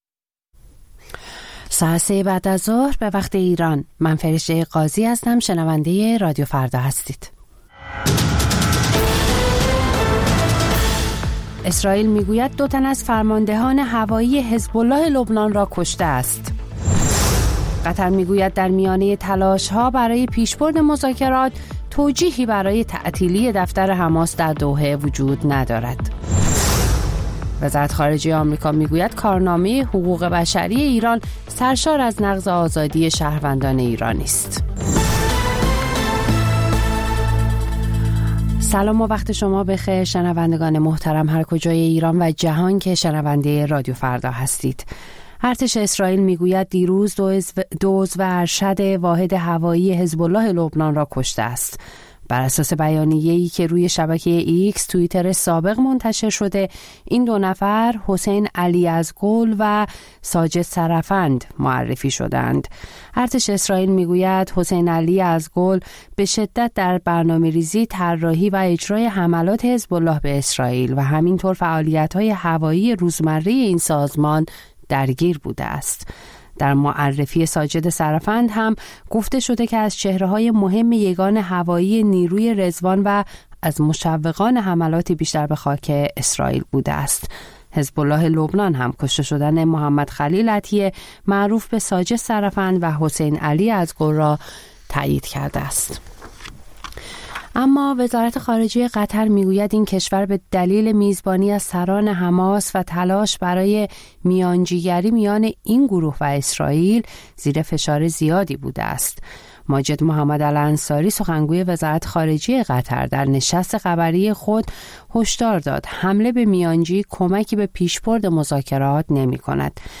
خبرها و گزارش‌ها ۱۵:۰۰